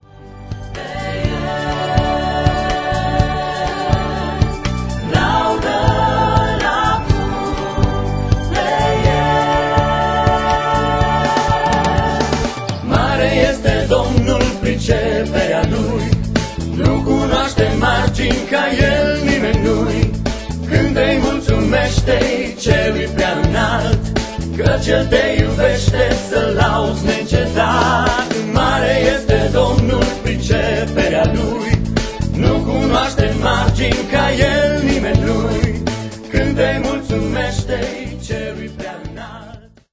Este un adevarat compendiu de lauda si inchinare.